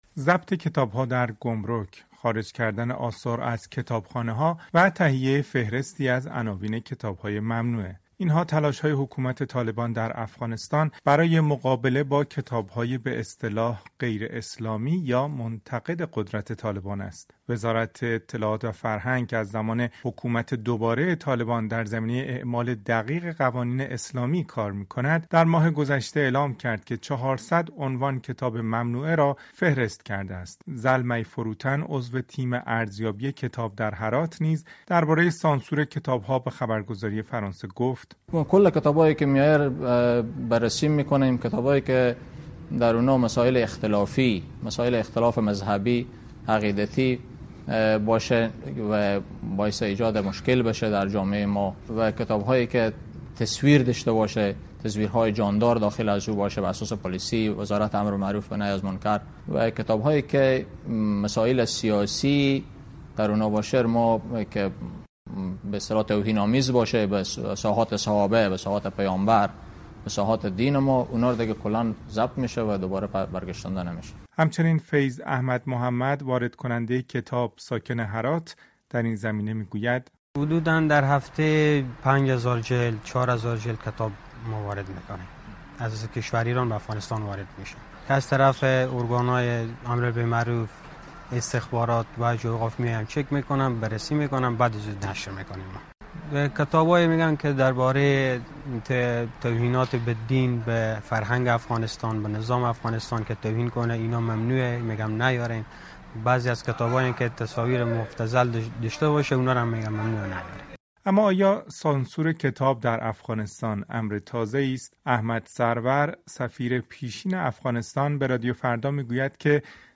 گزارش رادیویی «کتاب‌های ممنوعه» در افغانستان